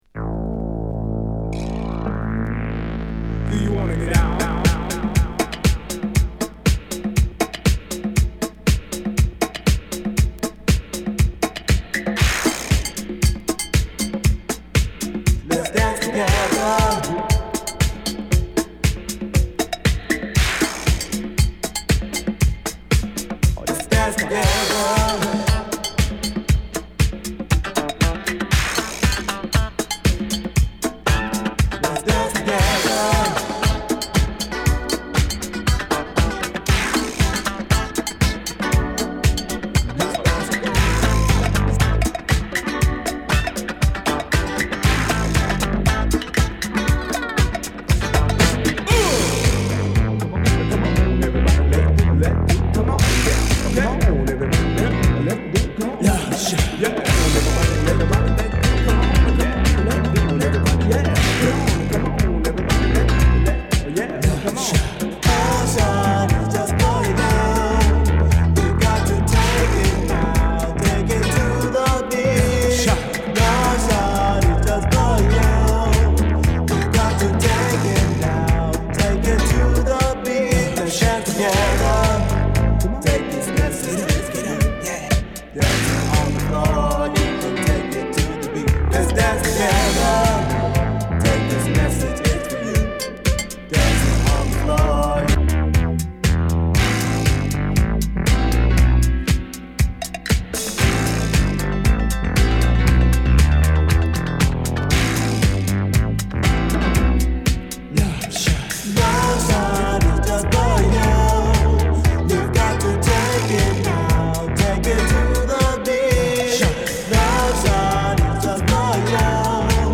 試聴はA1→B1→B2です。